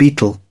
Transcription and pronunciation of the word "beetle" in British and American variants.